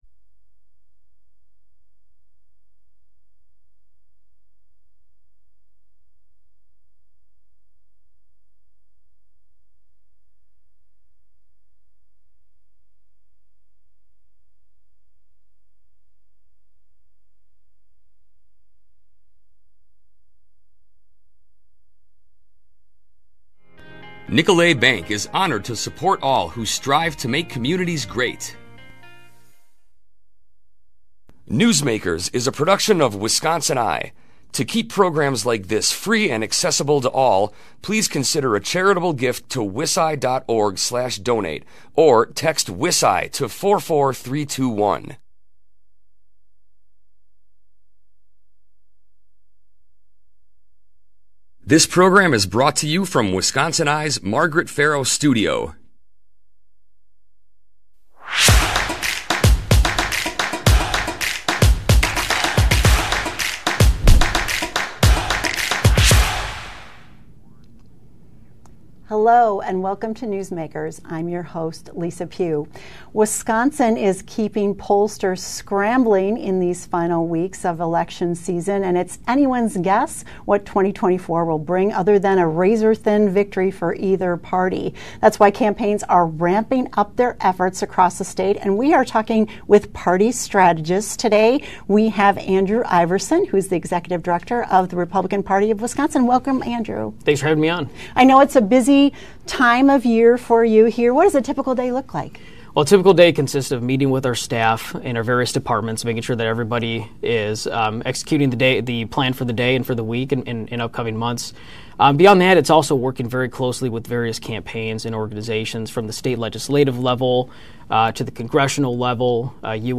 That’s why both campaigns are ramping up their efforts. We are sitting down with party strategists to understand what that looks like.